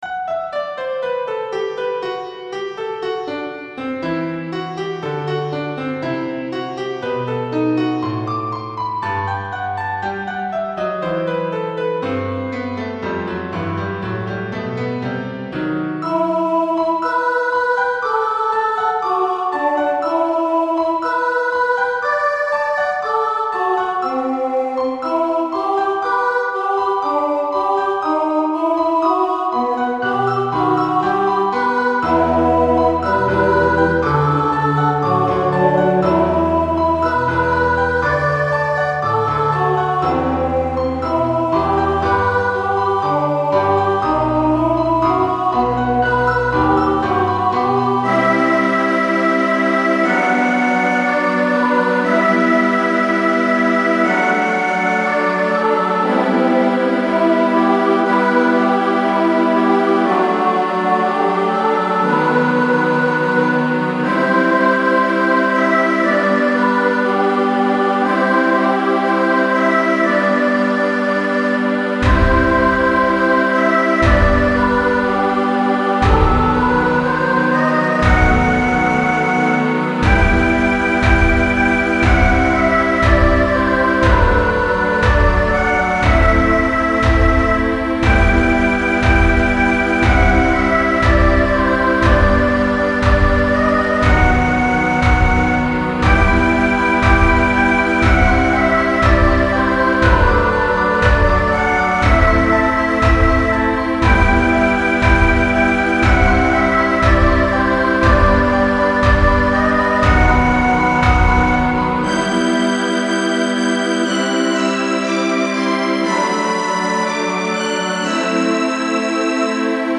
MIDとGuitarの曲